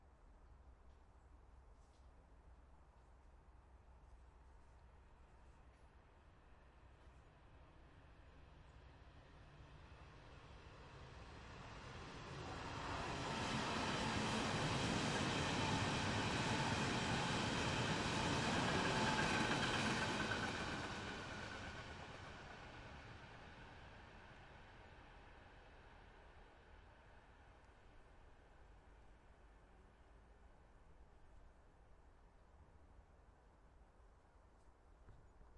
德国柏林Priesterweg的火车经过 " S Bahn城市火车经过近距离 6
描述：SBahn城市列车通过。在铁路轨道上方的桥上听到了。 2016年9月在柏林Priesterweg以Zoom HD2录制成90°XY
标签： 铁路 火车 城市列车 铁路 铁路 铁路 火车 电动火车 铁路 现场记录 轻轨 乘客列车
声道立体声